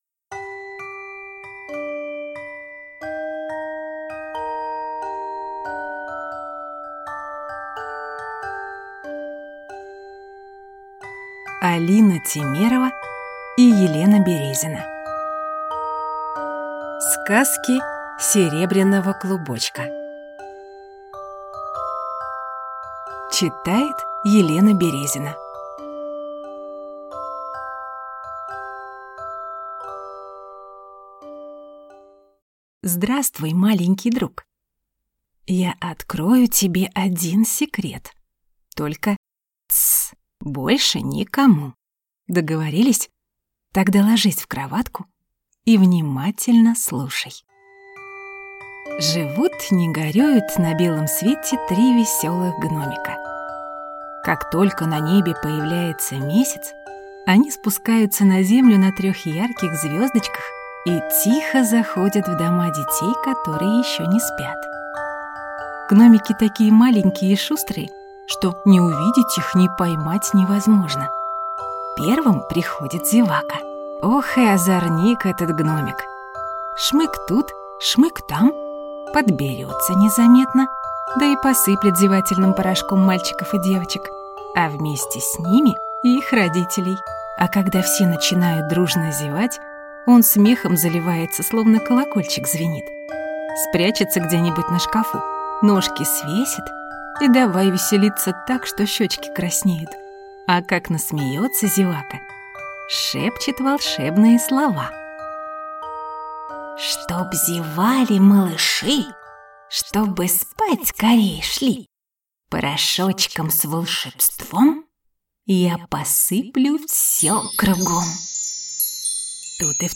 Аудиокнига Сказки серебряного клубочка | Библиотека аудиокниг